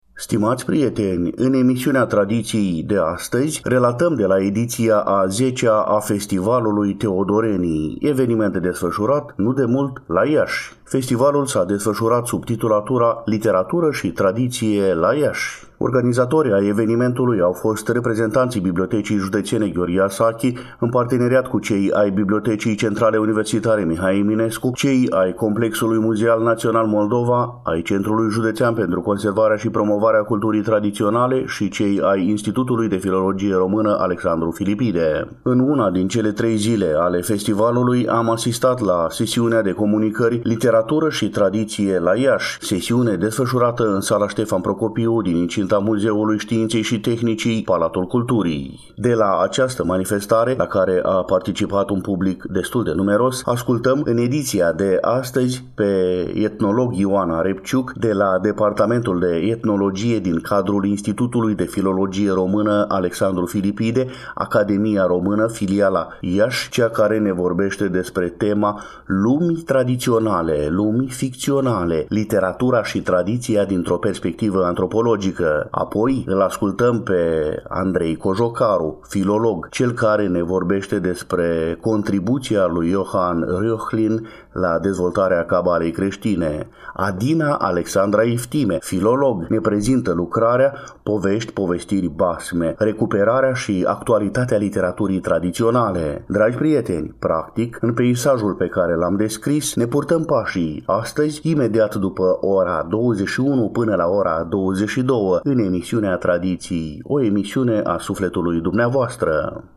În una din cele trei zile ale festivalului, am asistat la Sesiunea de comunicări „Literatură și Tradiție la Iași”, sesiune desfășurată în Sala „Ștefan Procopiu” din incinta Muzeului Științei și Tehnicii, Palatul Culturii.